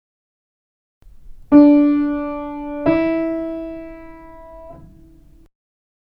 Tone
tono.mp3